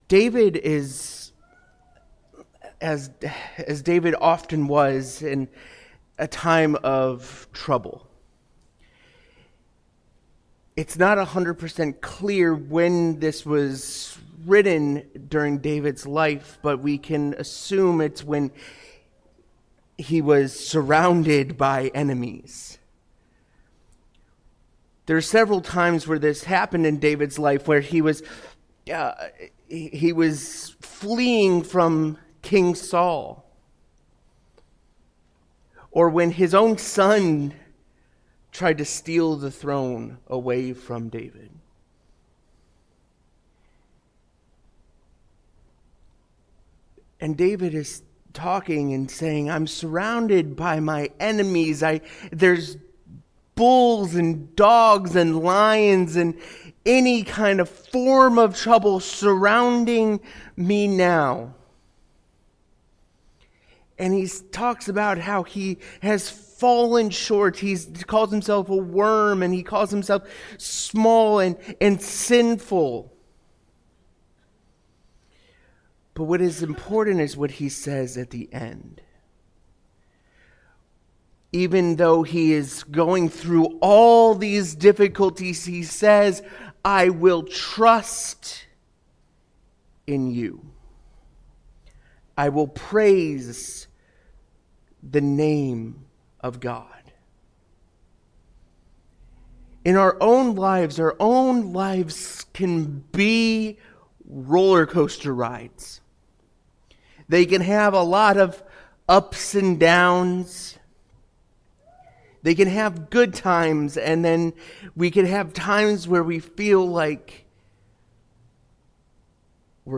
Sunday Sermons When in Doubt...